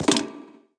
Grenadebounce Sound Effect
grenadebounce.mp3